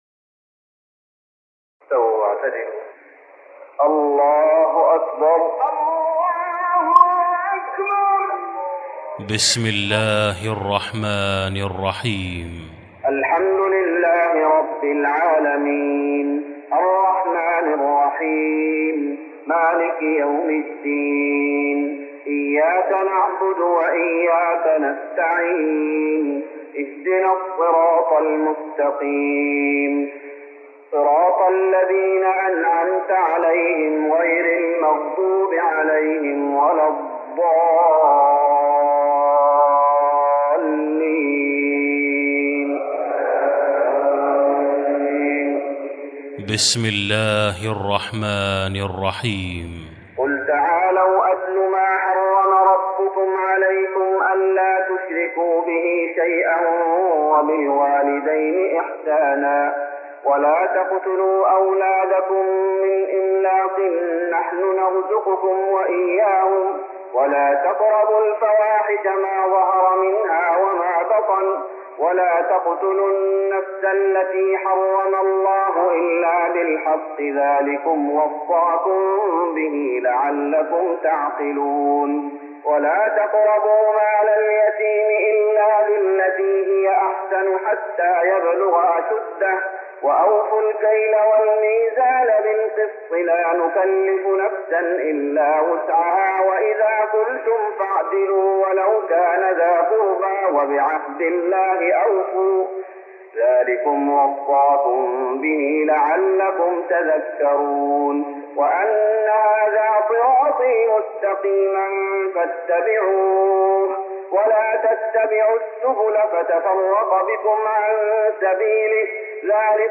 تراويح رمضان 1414هـ من سورة الأنعام (151-165) Taraweeh Ramadan 1414H from Surah Al-An’aam > تراويح الشيخ محمد أيوب بالنبوي 1414 🕌 > التراويح - تلاوات الحرمين